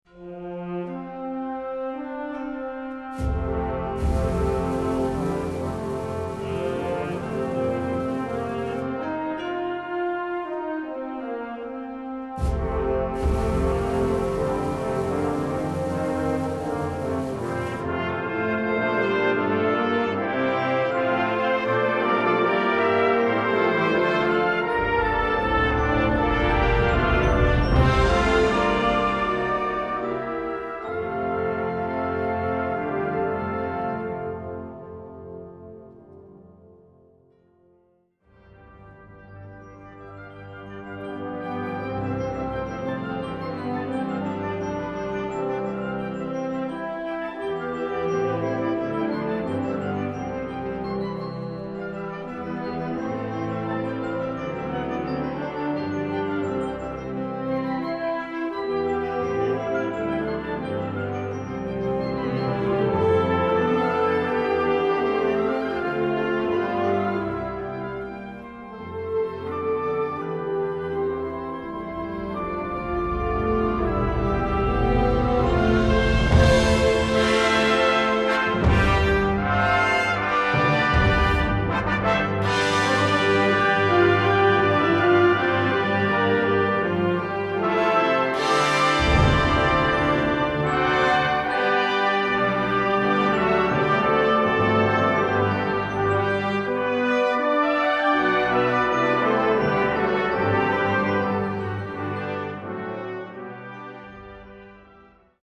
Répertoire pour Harmonie/fanfare - Concert Band ou Harmonie